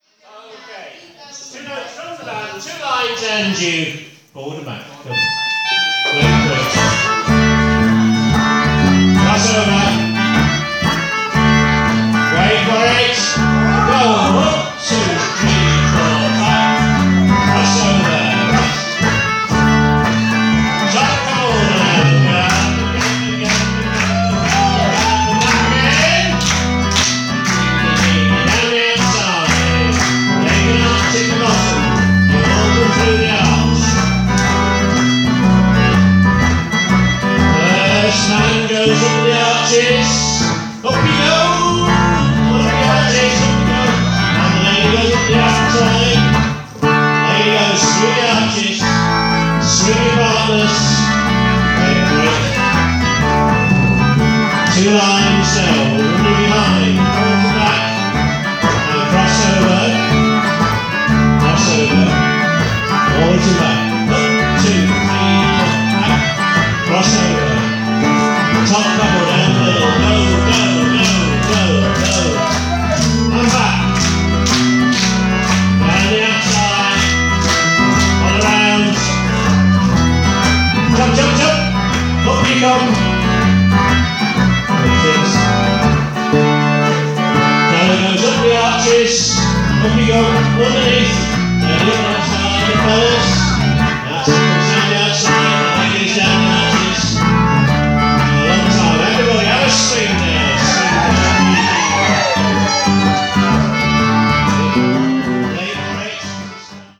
Our style is on the rocky/jazzy side of folk, though we are always sensitive to the audience's preferences on volume level.
Sweets of May (48 Jig).mp3